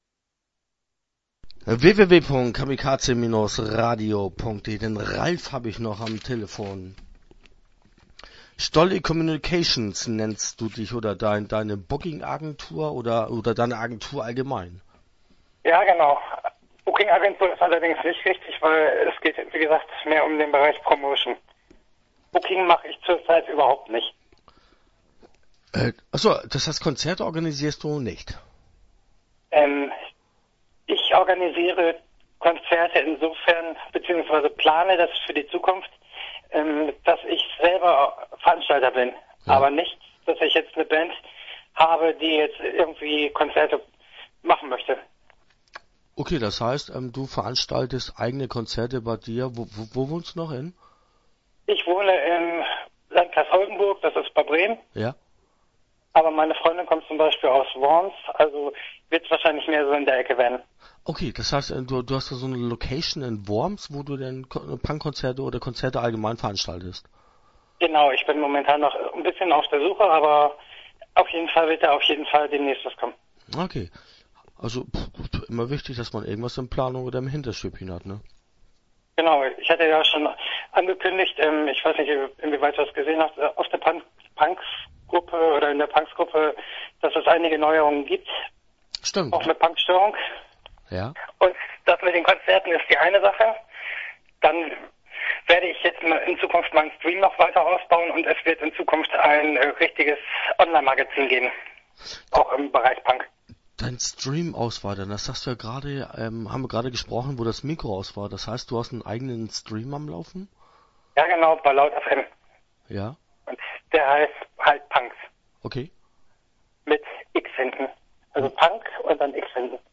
Start » Interviews » Stolle Communications